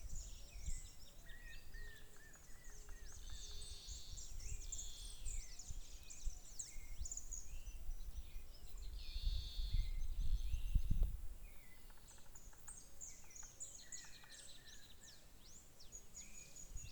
White-fronted Woodpecker (Melanerpes cactorum)
Country: Argentina
Location or protected area: Amaicha del Valle
Condition: Wild
Certainty: Recorded vocal